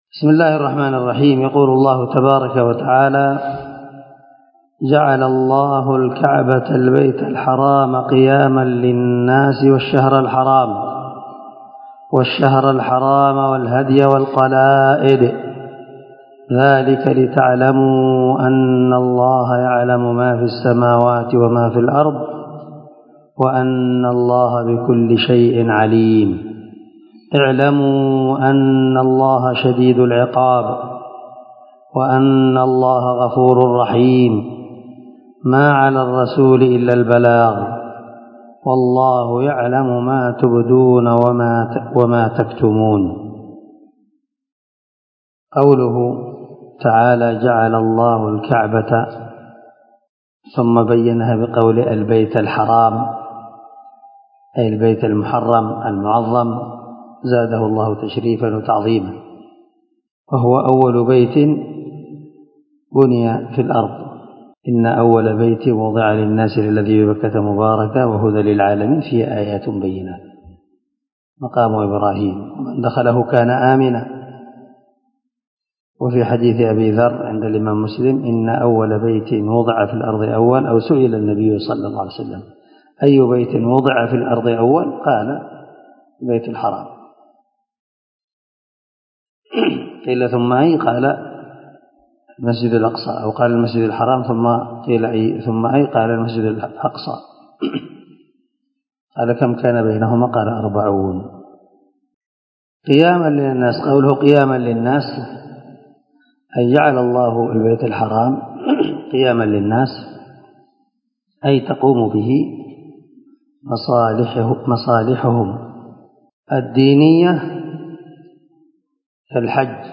385الدرس 51تفسير آية ( 94 – 96 ) من سورة المائدة من تفسير القران الكريم مع قراءة لتفسير السعدي
دار الحديث- المَحاوِلة- الصبيحة.